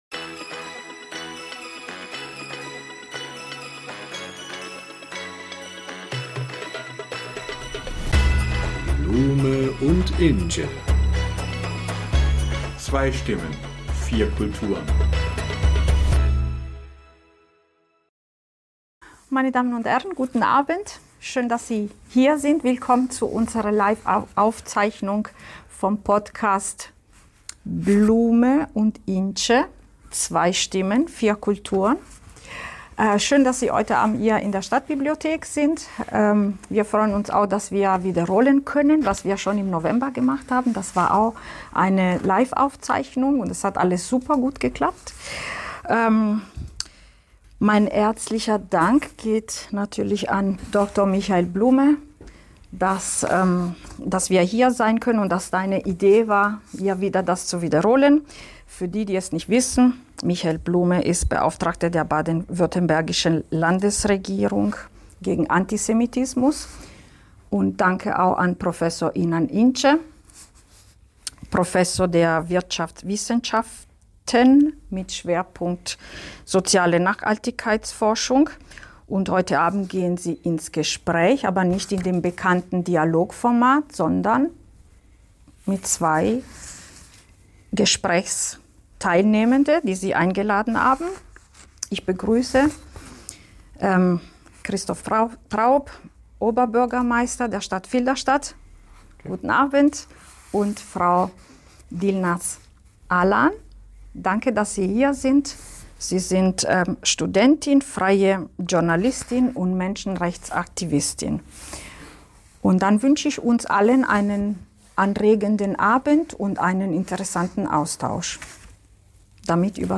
Insbesondere die derzeitige Lage in Rojava (Westkurdistan) und Rojhilat (Ostkurdistan) steht im Mittelpunkt der sachlichen Einordnung. Ferner rücken die Auswirkungen auf Minderheiten und die Zivilgesellschaft, die Rolle regionaler und internationaler Akteure, die menschenrechtliche Dimension, gesellschaftliche und religiöse Dynamiken in der Religion sowie sicherheitspolitische Aspekte für Deutschland in den Fokus des Gesprächs in der Stadtbibliothek.